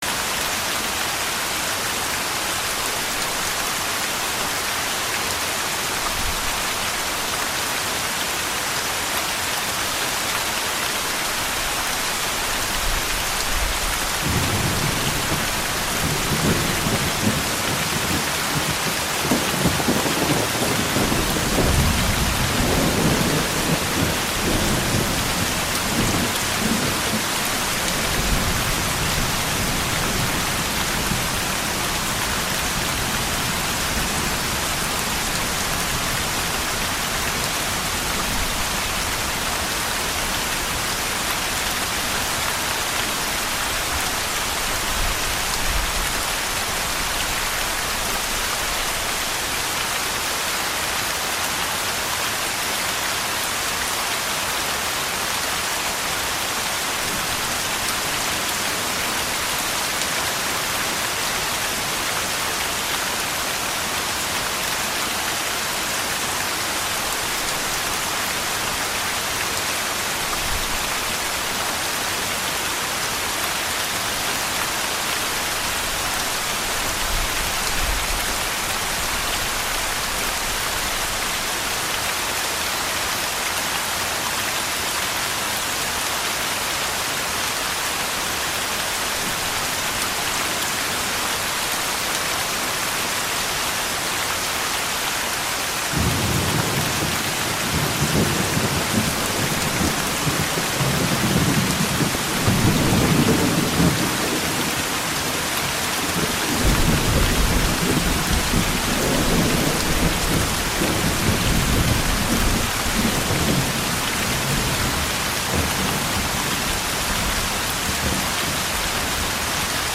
Dormez en 2 minutes | pluie sur toit en tôle et tonnerre intense